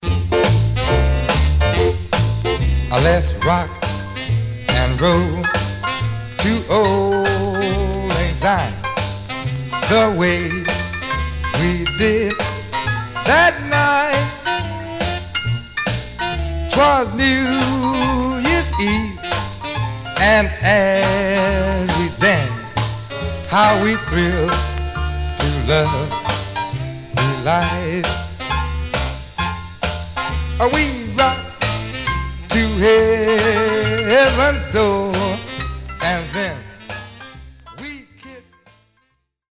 Mono
Rythm and Blues